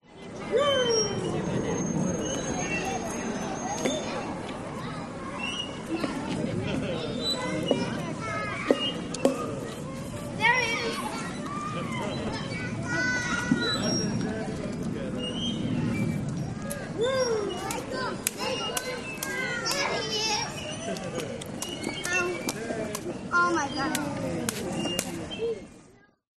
Звонкий смех и гомон детской площадки